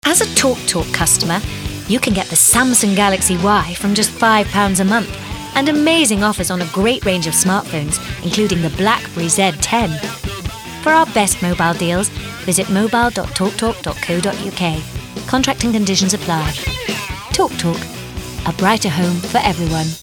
RP. Versatile characters, many accents & standard English. Bright, young, upbeat ads, also a singer.